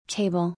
table /TA-ble/